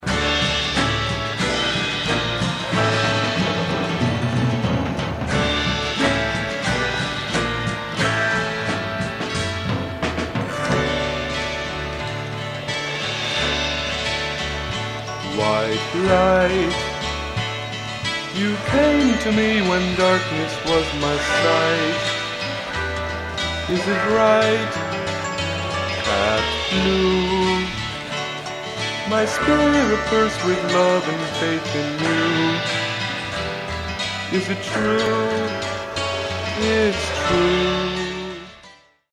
drums
piano, acoustic 6-string guitar, autoharp
acoustic 12-string guitar, bass, vocal